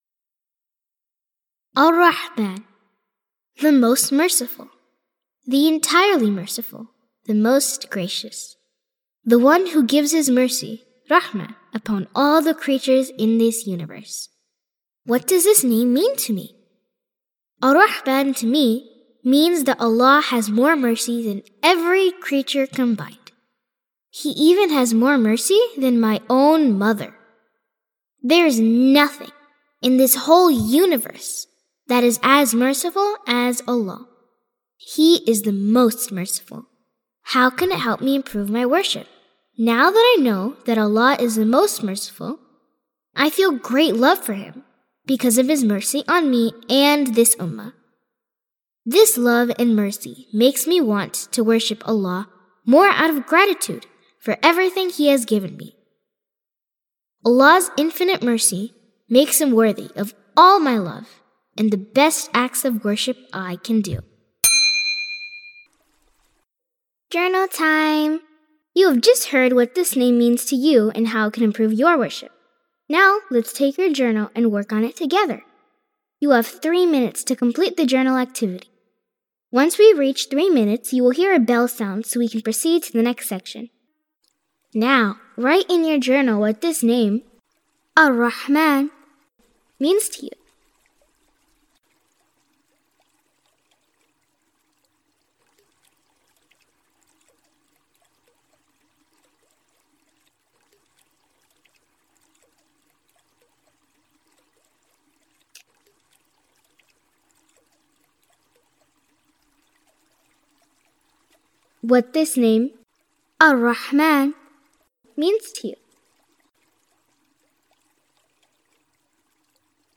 Audio Journal